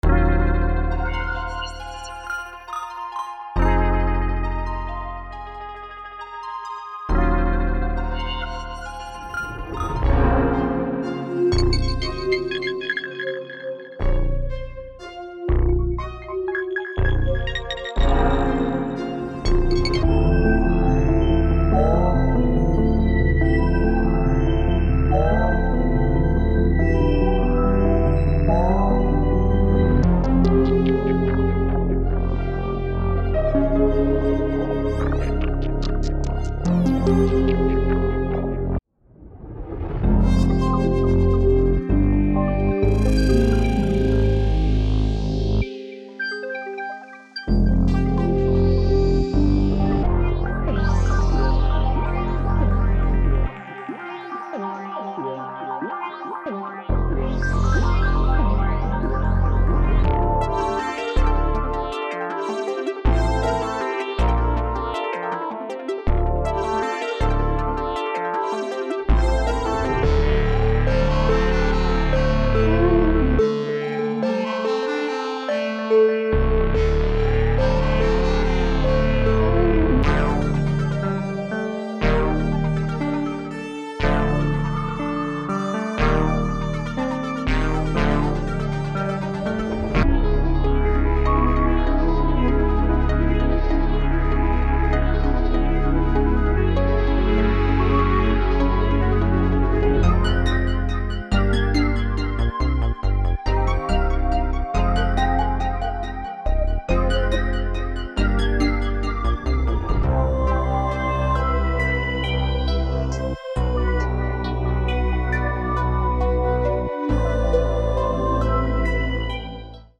Presenting Telekinetic: a collection of sci fi presets for Arturia’s Prophet-5 V
Taking inspiration from contemporary hiphop from the likes of Travis Scott and Mike Dean, alongside classic sci fi sonics, this bank will have you making crazy futuristic, psychedelic melodies with ease
From keys to leads, pads to textures, if you’re looking to capture that retro-futuristic tone in your music, you’ll find exactly what you’re looking for
telekinetic-full-audio-demo.mp3